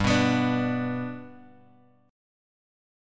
G chord {3 2 x 4 3 x} chord
G-Major-G-3,2,x,4,3,x.m4a